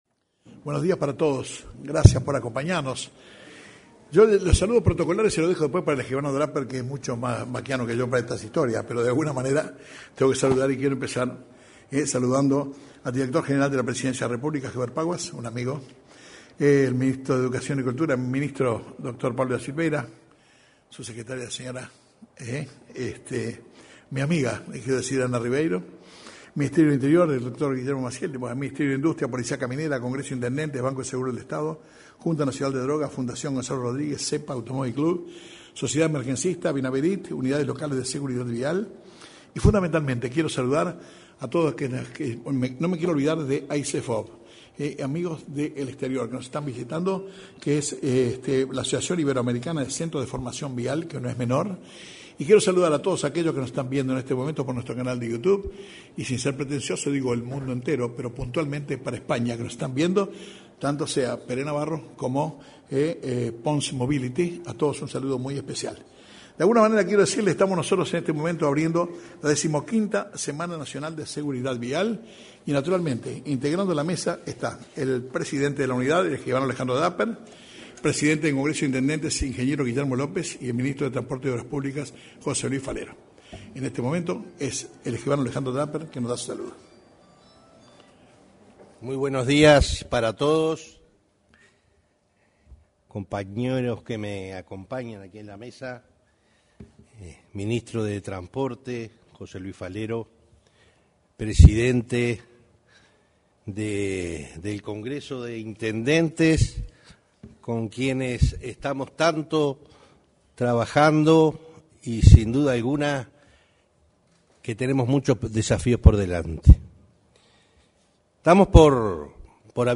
Este martes 25, en el salón de actos de la Torre Ejecutiva, se inauguró la XV Semana Nacional de la Seguridad Vial.
En la oportunidad, se expresaron el presidente de la Unidad Nacional del área (Unasev), Alejandro Draper; el presidente del Congreso de Intendentes, Guillermo López, y el ministro de Transporte y Obras Públicas, José Luis Falero. Participo, vía Zoom, el director general de Tráfico de España, Pere Navarro.